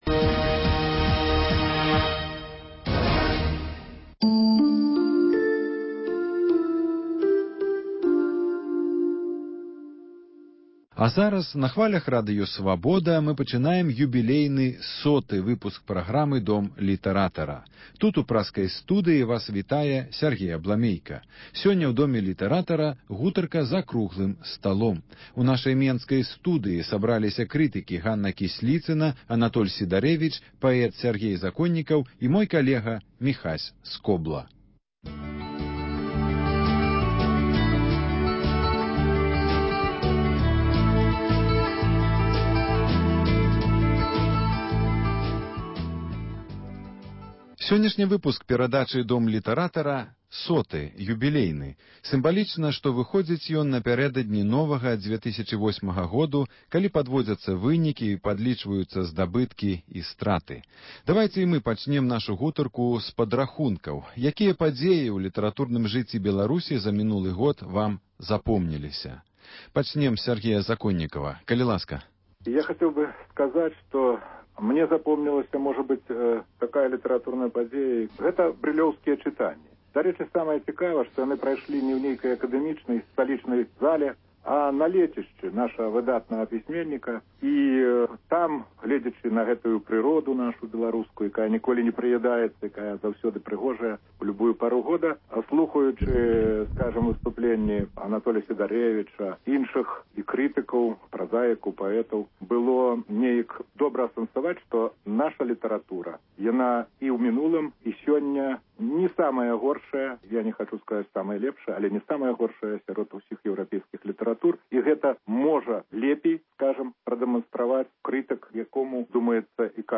круглы стол